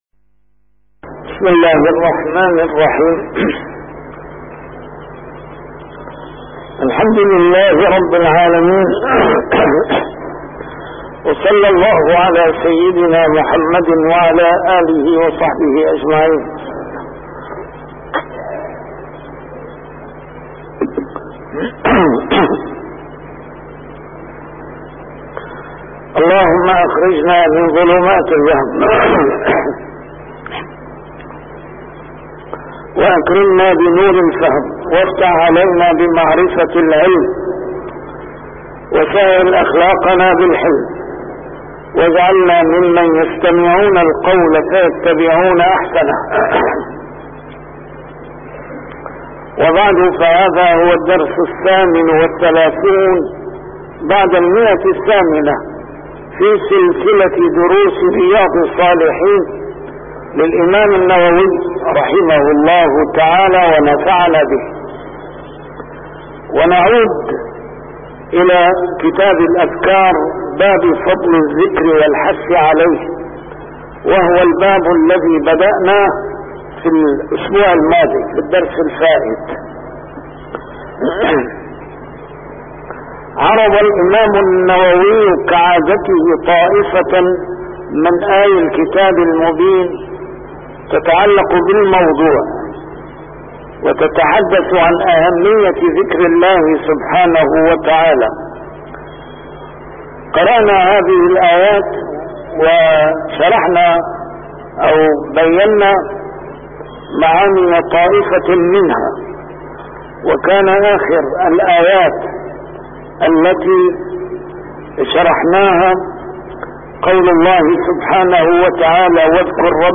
A MARTYR SCHOLAR: IMAM MUHAMMAD SAEED RAMADAN AL-BOUTI - الدروس العلمية - شرح كتاب رياض الصالحين - 838- شرح رياض الصالحين: فضل الذكر والحث عليه